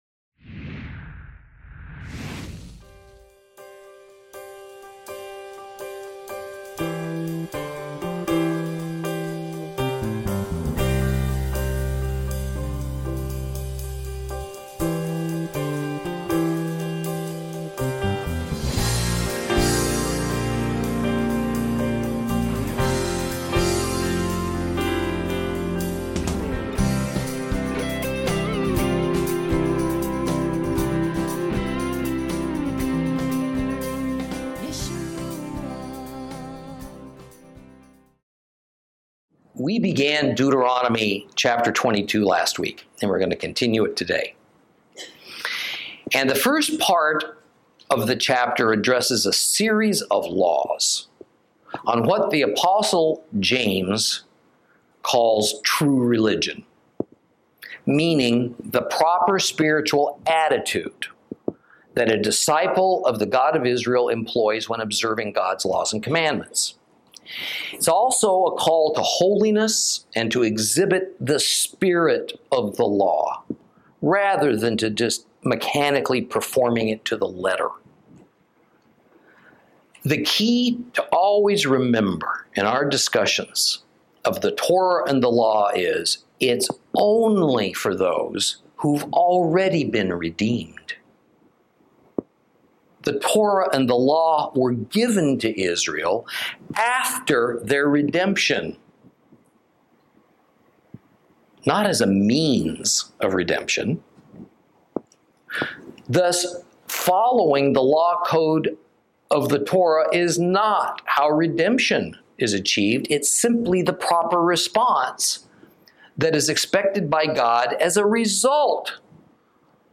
Lesson 29 Ch22 - Torah Class